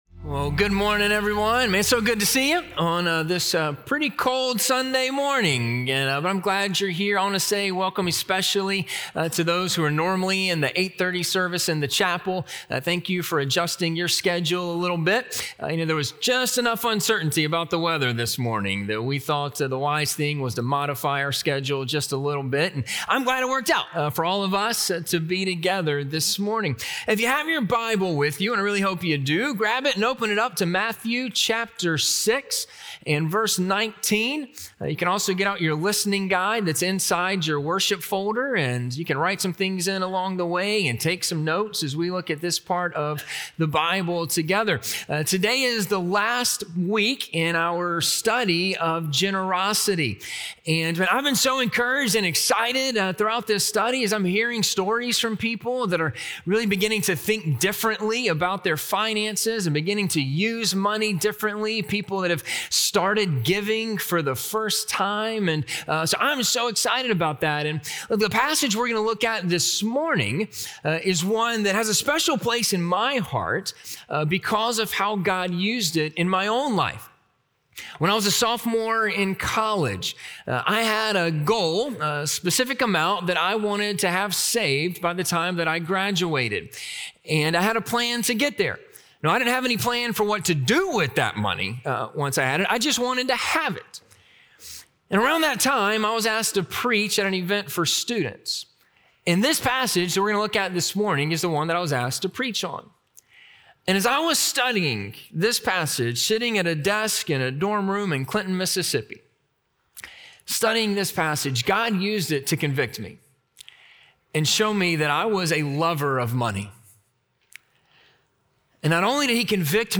We Invest in Eternity - Sermon - Ingleside Baptist Church